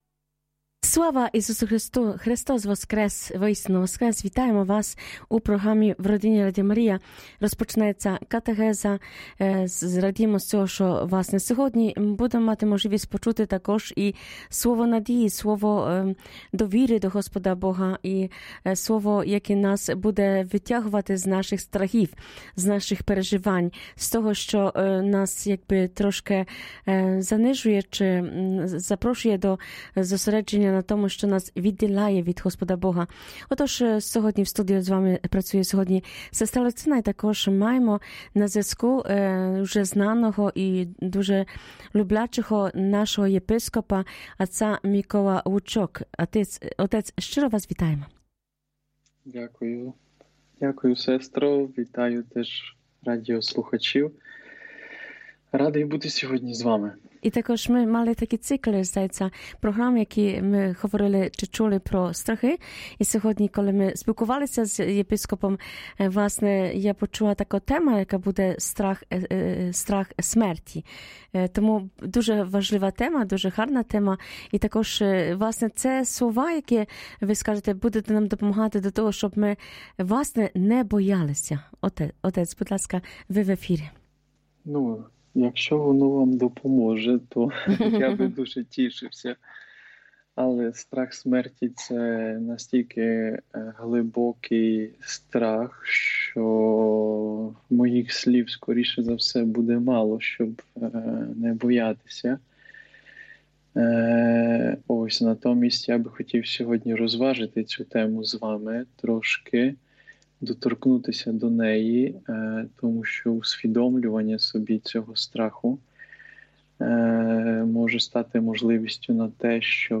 Єпископ Микола Лучок в катехизі дуже конкретно пояснює причину страху смерті. Священник наголошує на істині: Ісус знищив своєю смертю того, хто мав владу смерті – диявола.